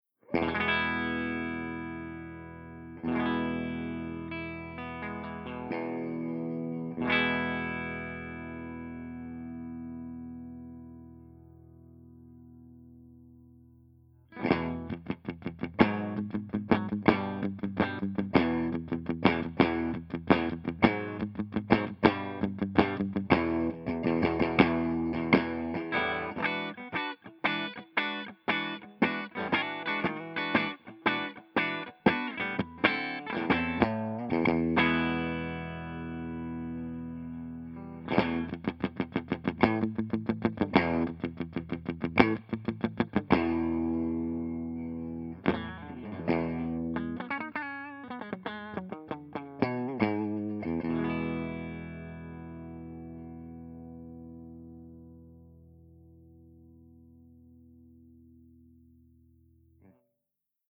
078_MARSHALLJTM60_CLEANBRIGHT_REVERB_HB
078_MARSHALLJTM60_CLEANBRIGHT_REVERB_HB.mp3